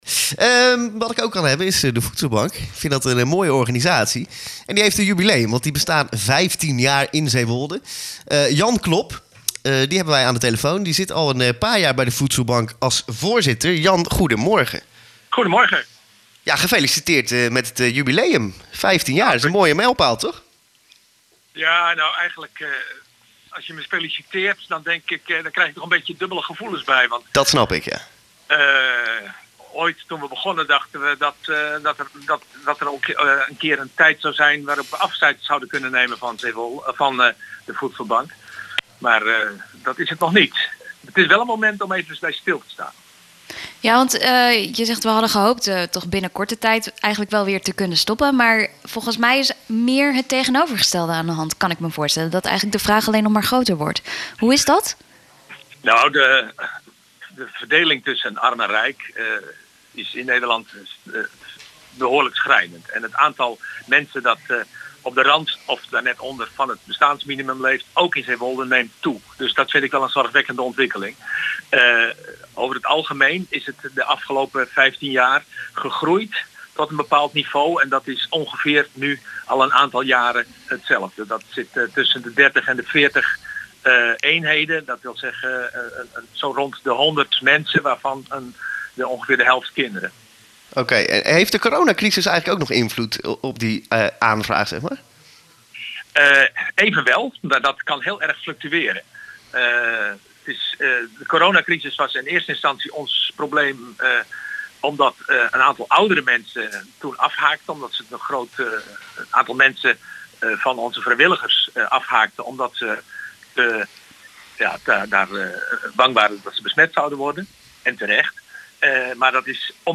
in het radioprogramma Weekendcafé van de Lokale Omroep van Zeewolde en Omroep Flevoland